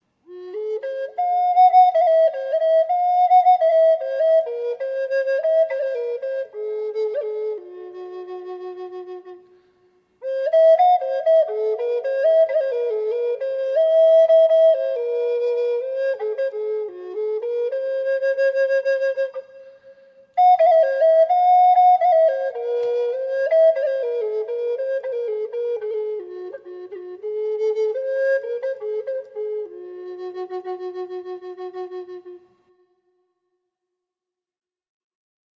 key of F#